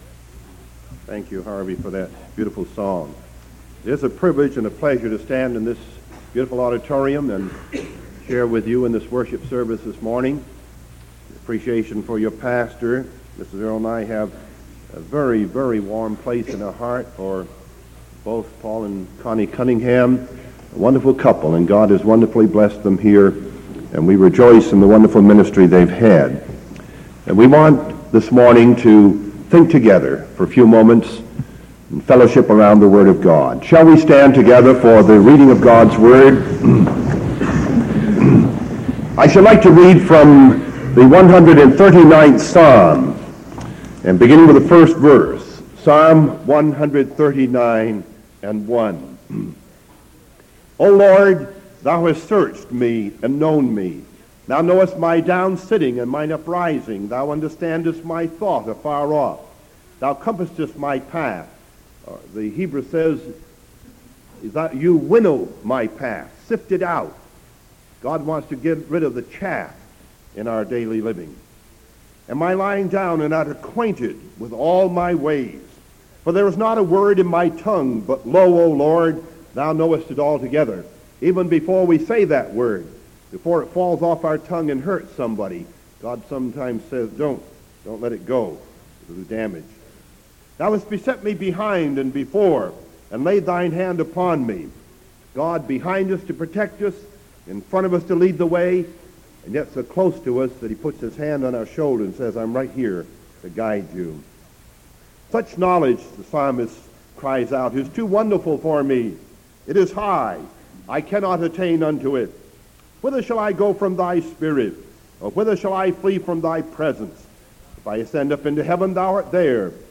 Sermon October 7th 1973 AM | Nazarene Theological Seminary